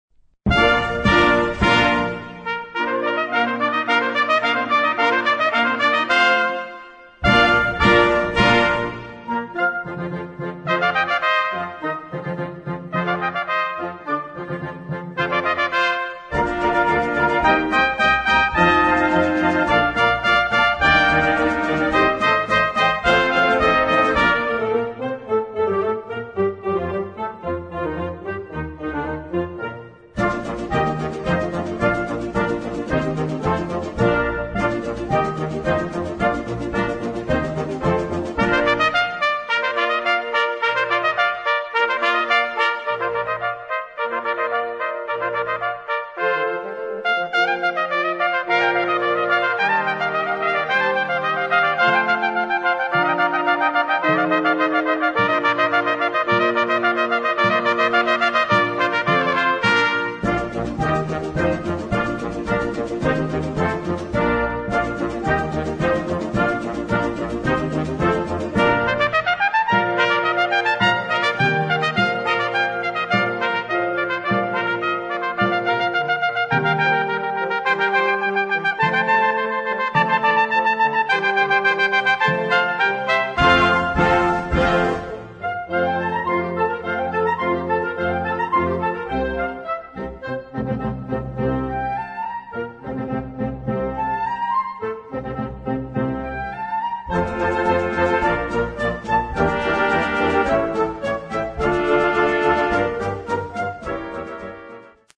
Allegro movement
concerto for two trumpets